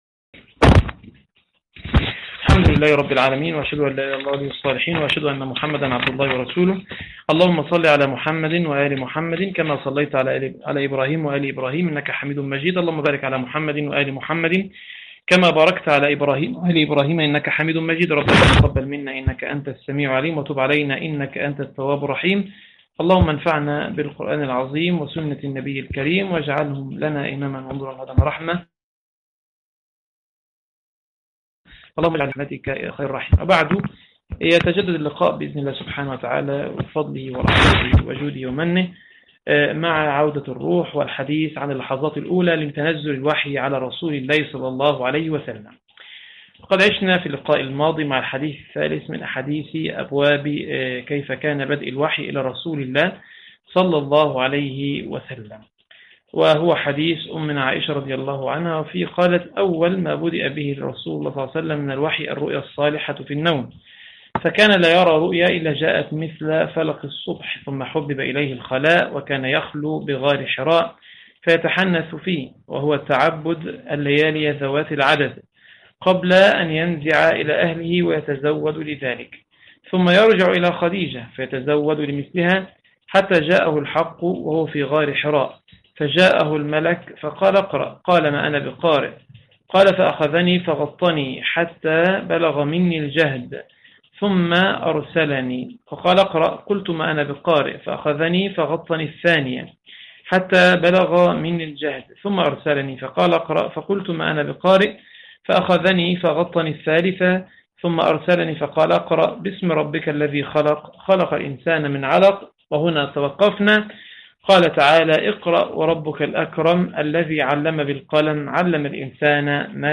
تفاصيل المادة عنوان المادة الدرس 5 | عودة الروح تاريخ التحميل الجمعة 15 اغسطس 2025 مـ حجم المادة 18.21 ميجا بايت عدد الزيارات 53 زيارة عدد مرات الحفظ 31 مرة إستماع المادة حفظ المادة اضف تعليقك أرسل لصديق